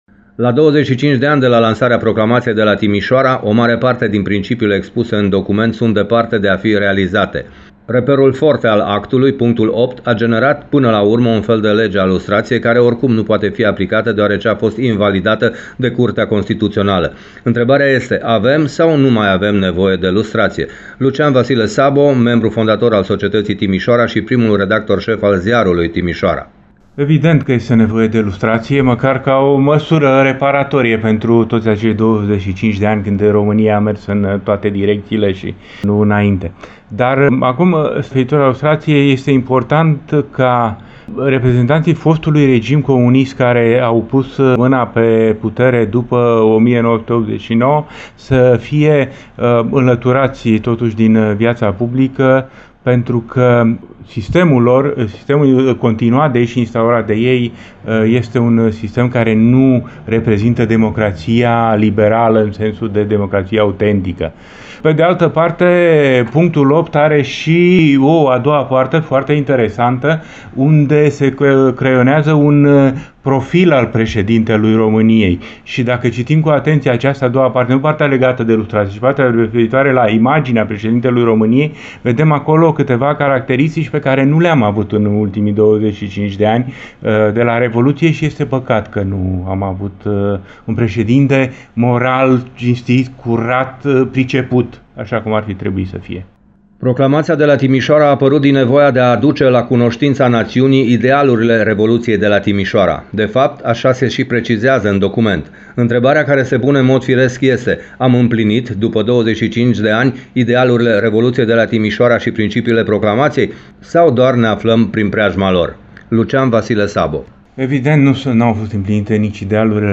UPDATE sinteza emisiunii